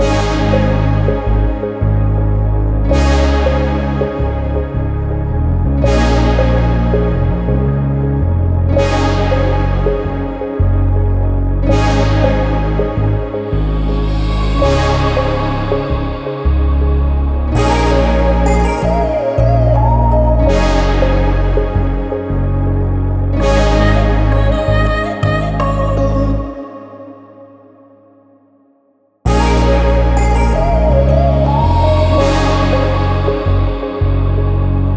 Жанр: Поп / K-pop / Музыка из фильмов / Саундтреки